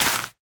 Minecraft Version Minecraft Version latest Latest Release | Latest Snapshot latest / assets / minecraft / sounds / block / sweet_berry_bush / break3.ogg Compare With Compare With Latest Release | Latest Snapshot